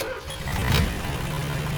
boatengine_start.wav